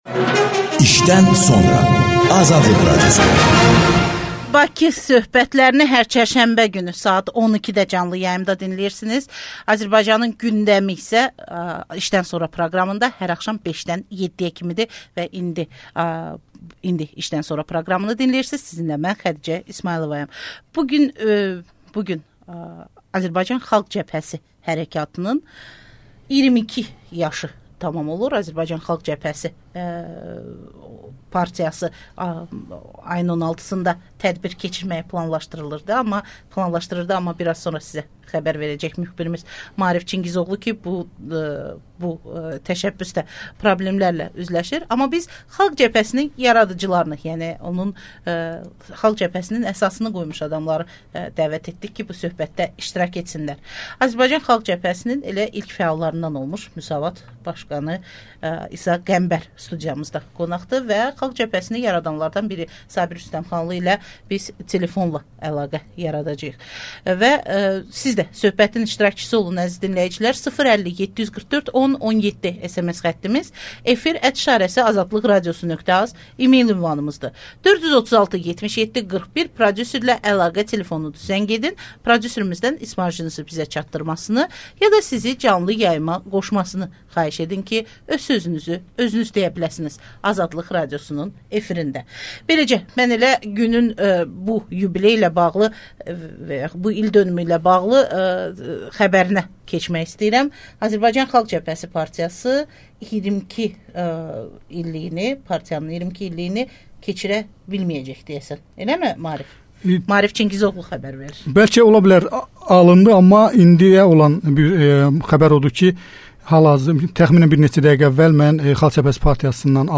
«İşdən sonra» proqramında AXC-nin fəallarından olmuş Müsavat başqanı İsa Qəmbər və Vətəndaş Həmrəyliyi Partiyasının sədri Sabir Rüstəmxanlı yaxın keçmişdən danışırlar